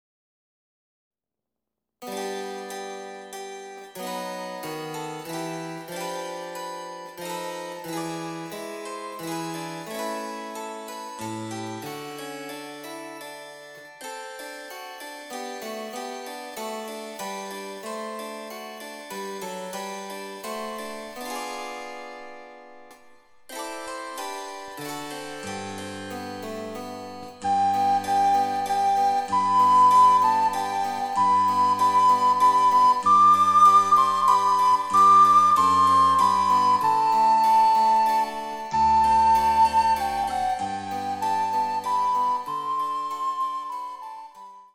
・チェンバロ伴奏
・リコーダー演奏例